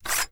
katana-sound-file.wav